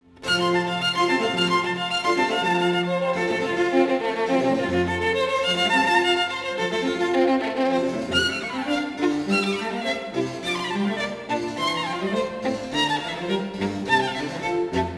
Much use is made of 'double-stopping' (playing two notes simultaneously) in the first solo.
The second solo section has the violin representing 'The Drunkard', with descending semiquaver arpeggios followed by descending demi-semiquaver scales. the music portrays the drunkard swaying about.